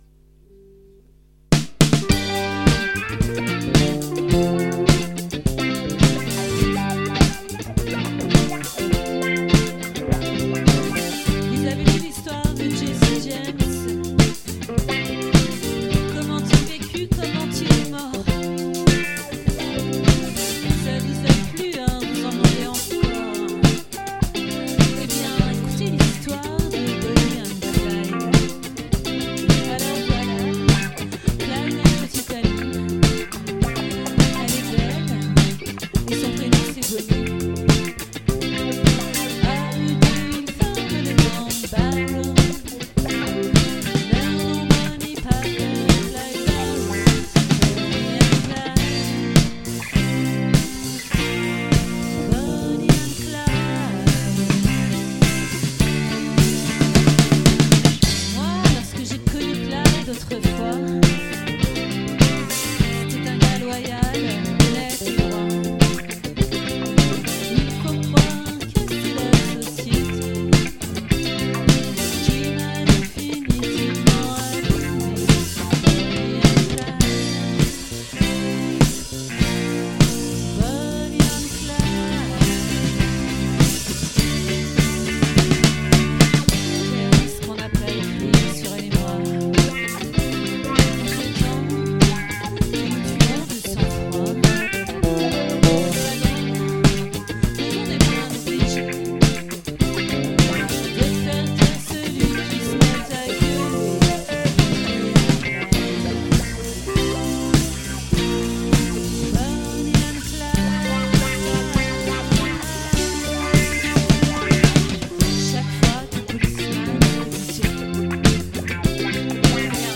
🏠 Accueil Repetitions Records_2022_11_16_OLVRE